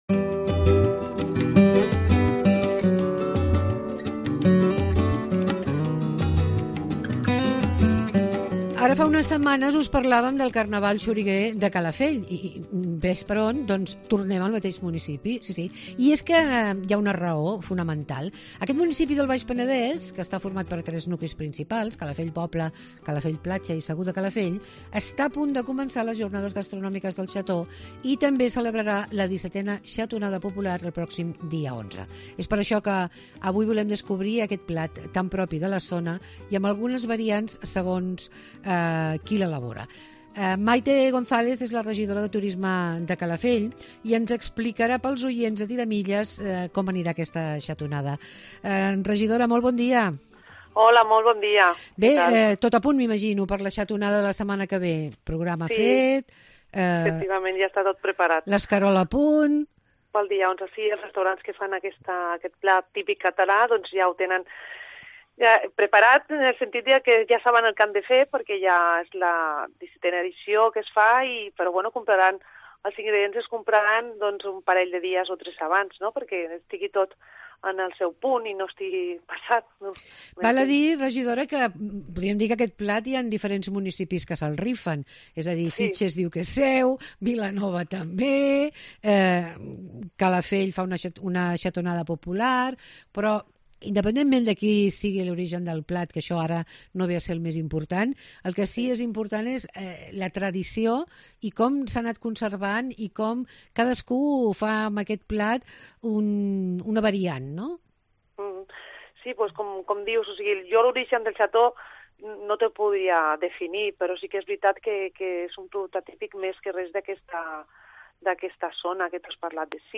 AUDIO: Comença la Ruta del Xató. Parlem amb la regidora de Turisme de Calafell, Maria Teresa González.